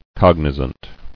[cog·ni·zant]